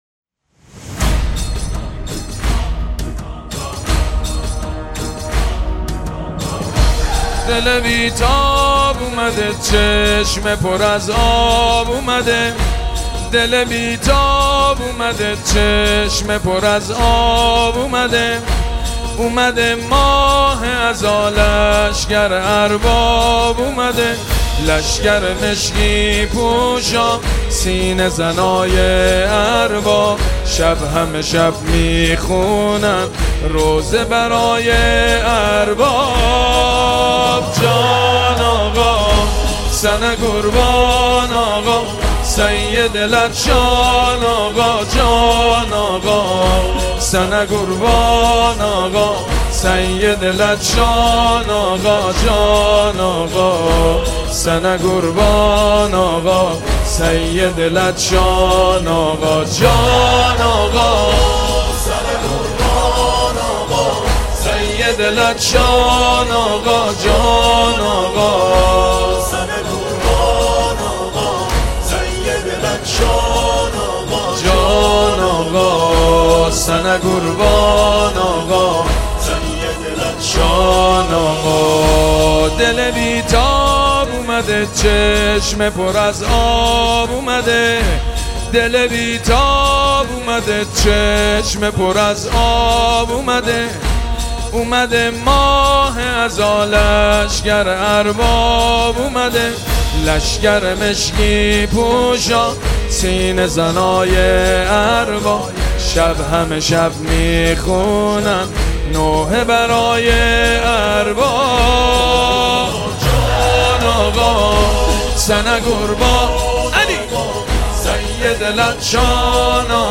دانلود نوحه کیفیت 320